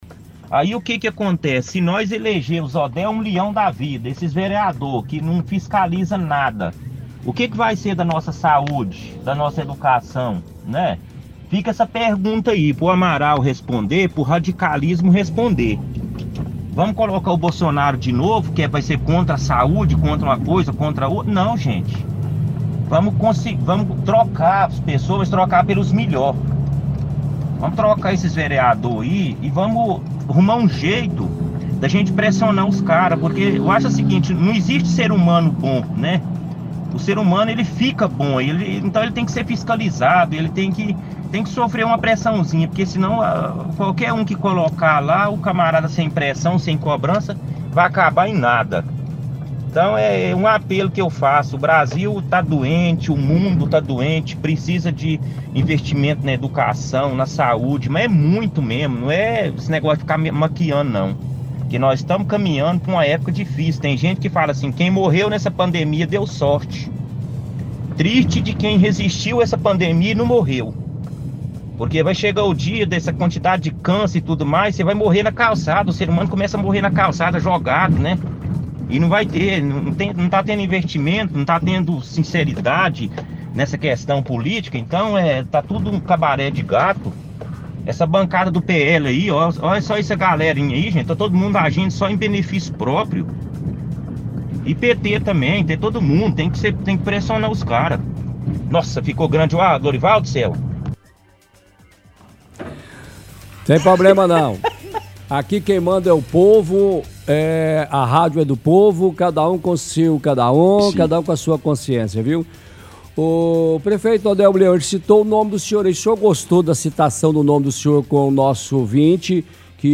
– Ouvinte diz que precisa dar uma “limpa” na política, critica o prefeito Odelmo Leão e os vereadores, que só votam a favor dos projetos do atual prefeito.